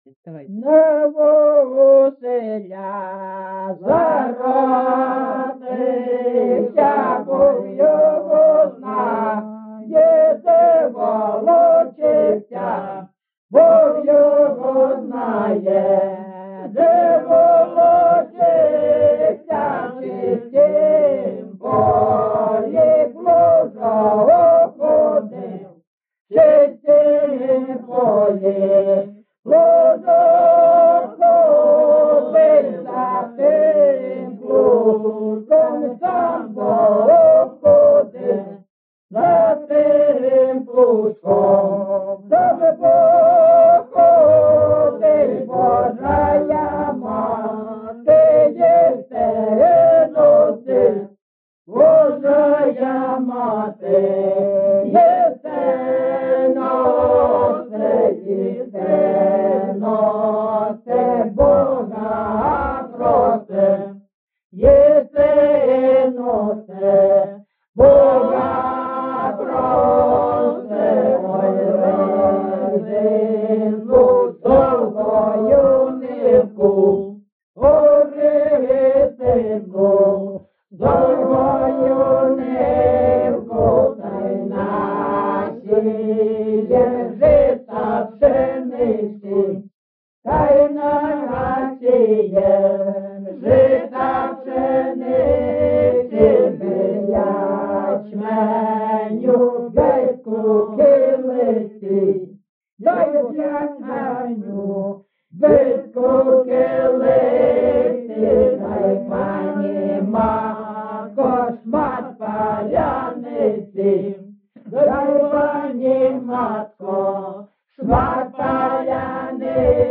People's amateur folklore group "Kalynonka" Lyman SBK